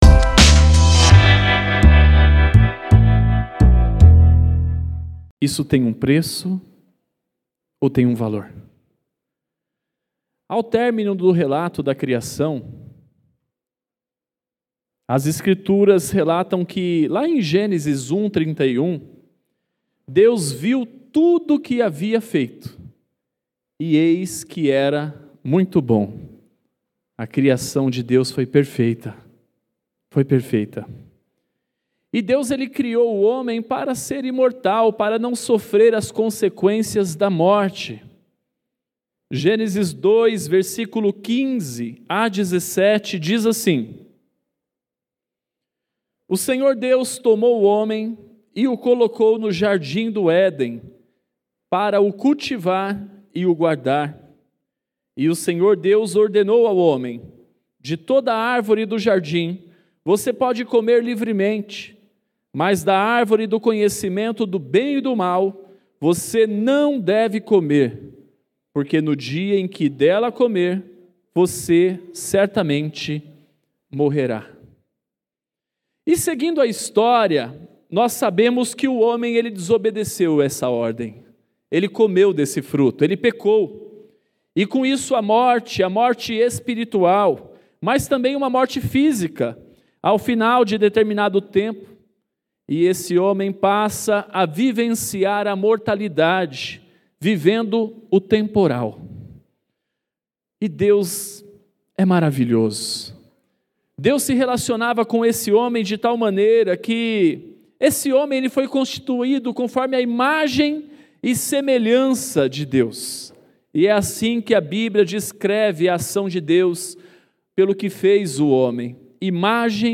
Mensagem especial de Páscoa, realizada na Sexta Feira da Paixão.